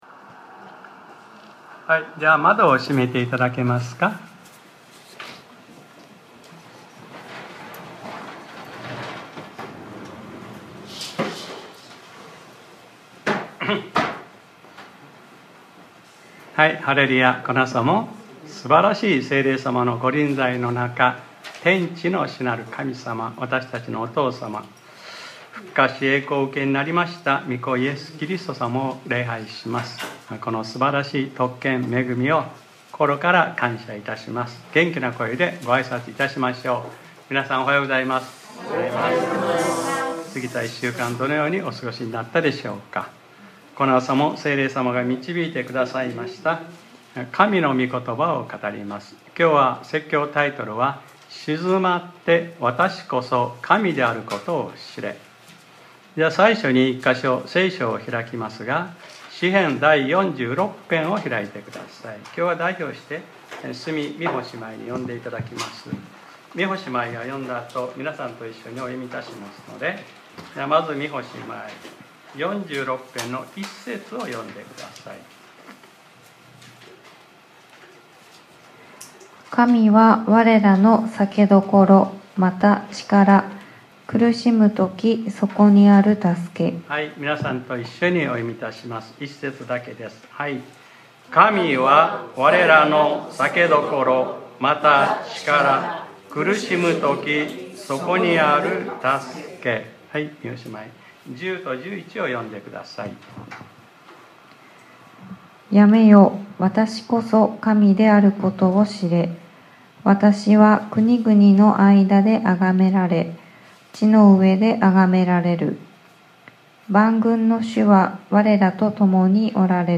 2025年09月28日（日）礼拝説教『 静まってわたしこそ神であることを知れ 』